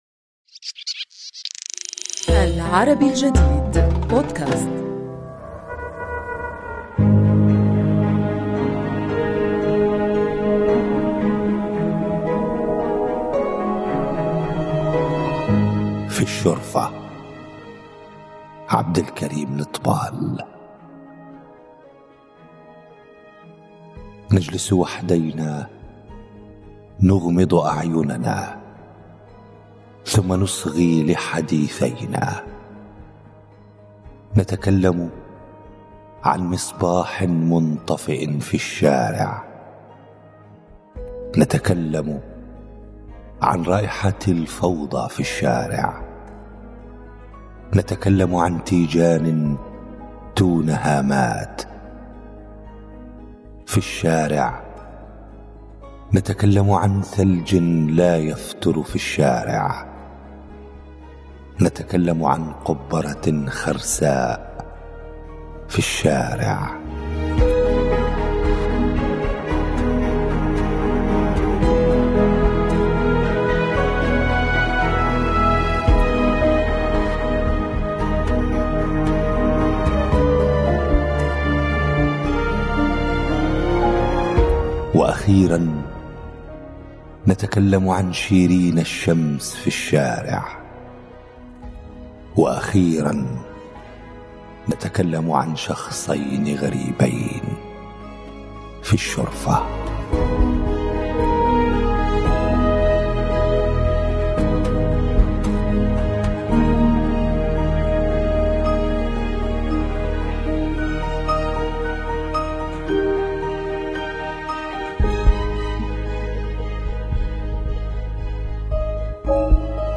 قراءة